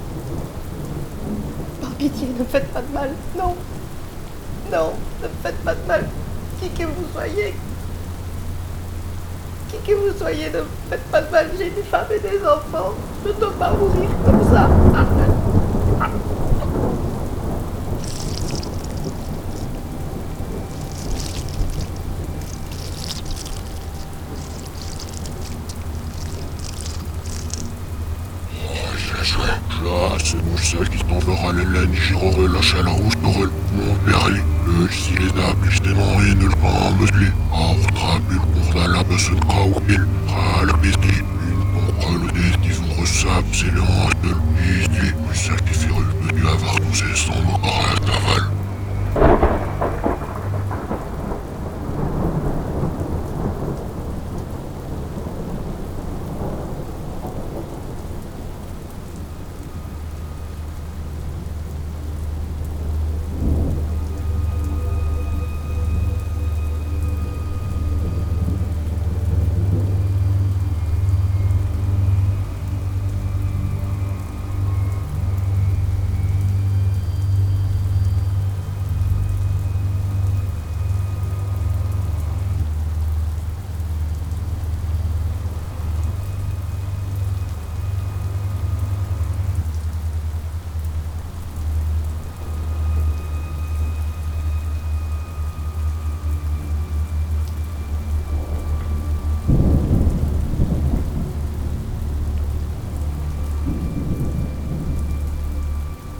• De nombreux cris pré-enregistrés ont été utilisés pour donner l'impression que les passagers du train étaient face à quelque chose d'horrible et d'indicible.